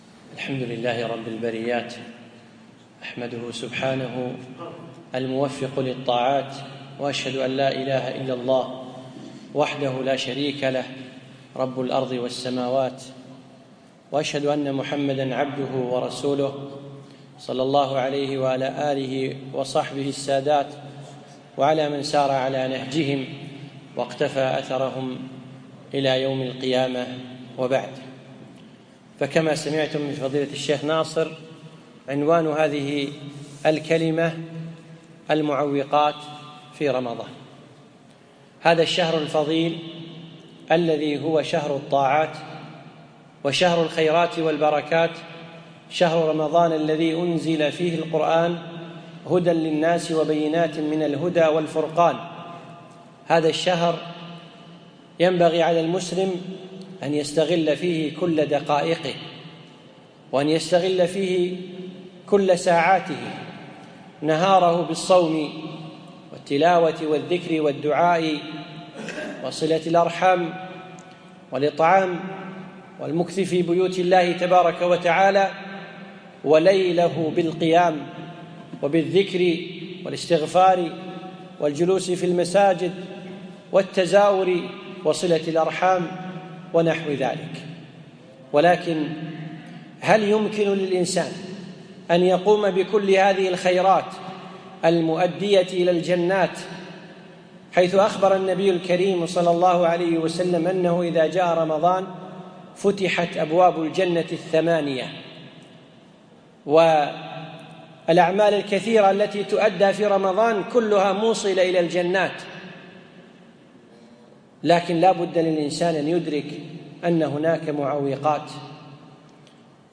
يوم الأربعاء 25 شعبان 1437هـ الموافق 2 6 2016م في مسجد الشلاحي الأندلس